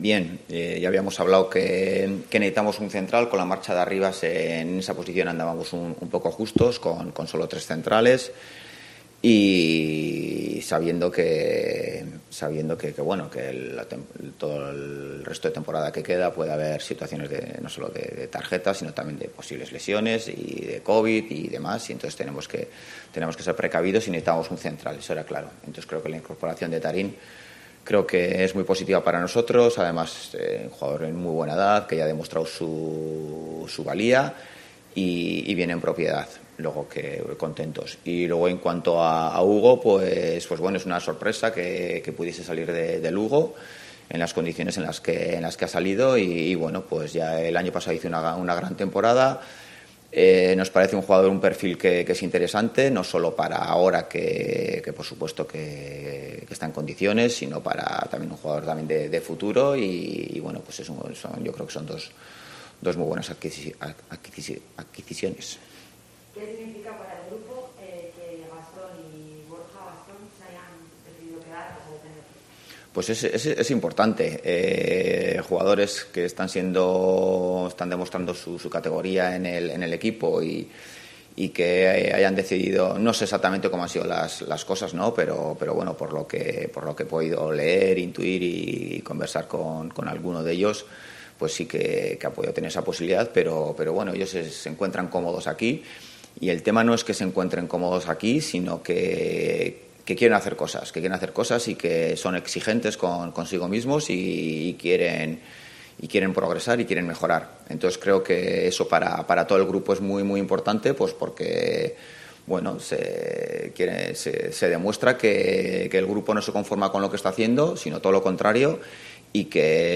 Rueda de prensa Ziganda (previa Amorebieta-Oviedo)